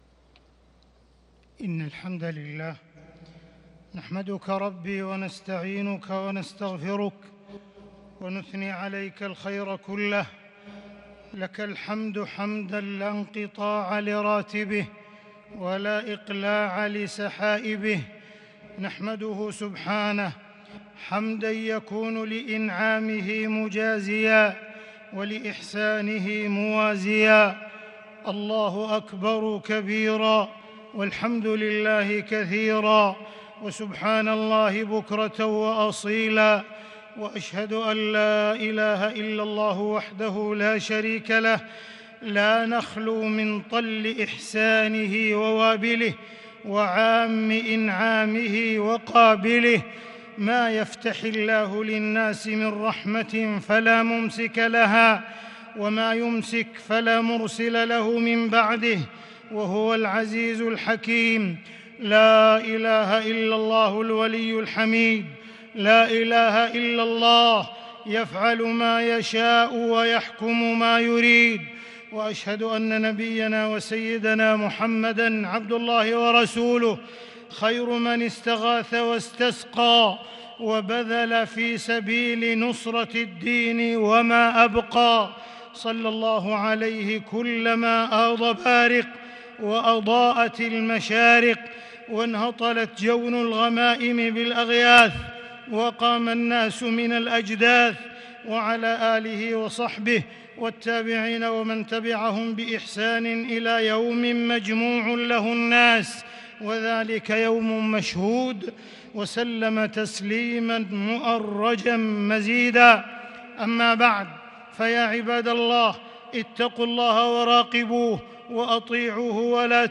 خطبة الإستسقاء 9 جمادى الأولى 1443هـ > خطب الاستسقاء 🕋 > المزيد - تلاوات الحرمين